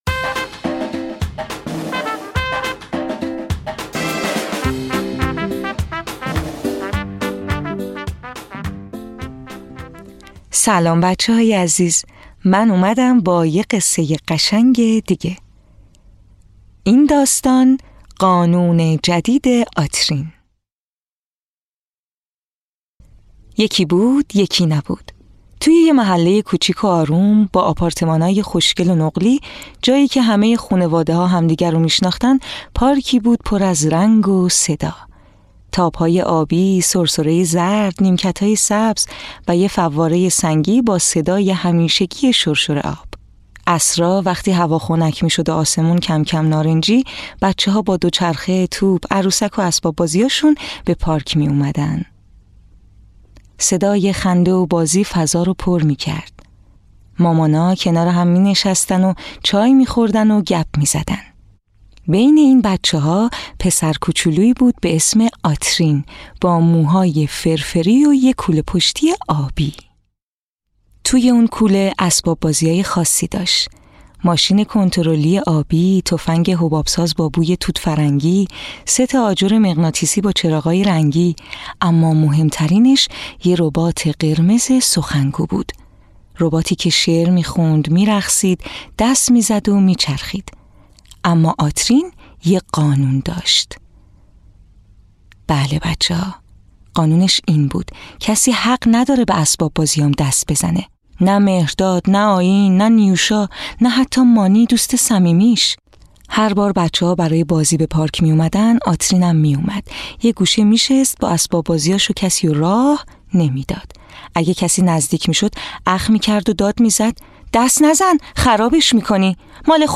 قصه های کودکانه صوتی – این داستان: قانون جدید آترین
تهیه شده در استودیو نت به نت